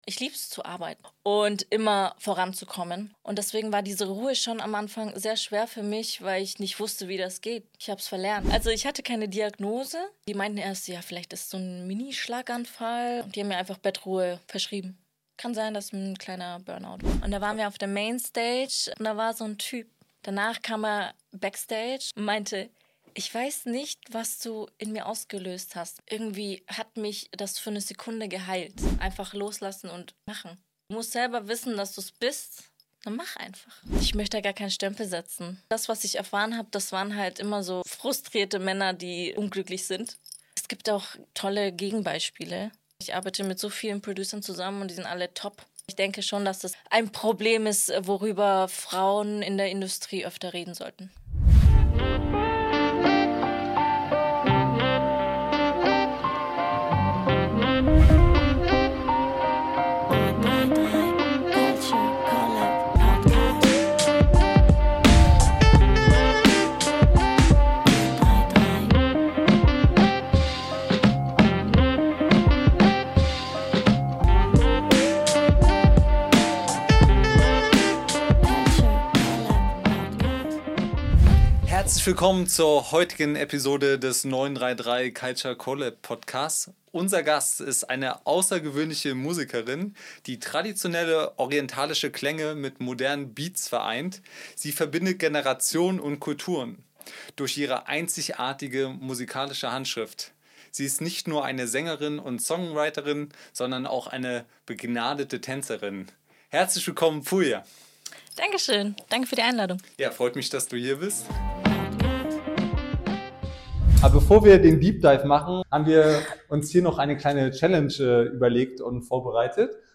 _____________________________________________________  Bei 933 CULTURE CO:LAB trifft Culture auf Mindset – echte Gespräche mit kreativen Köpfen, die Großes bewegen.